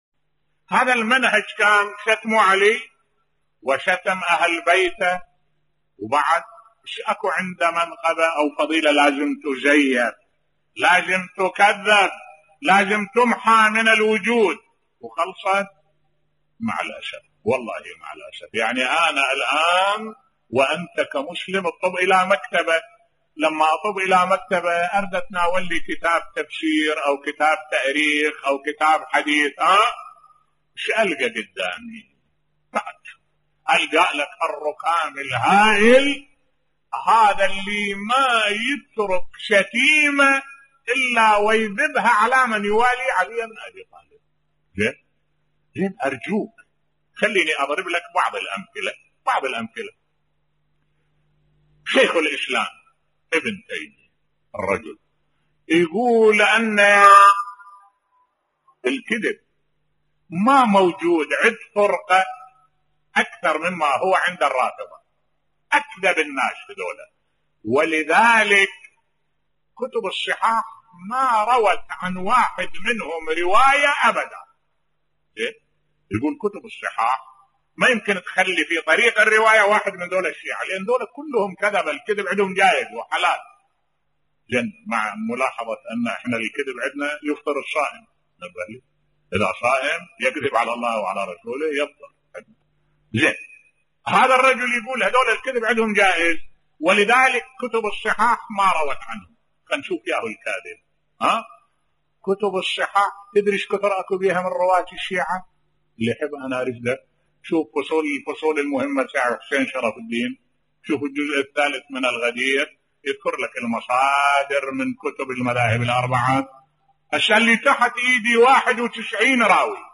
ملف صوتی يرد قول ابن تيميه أن الشيعة أكذب الناس بصوت الشيخ الدكتور أحمد الوائلي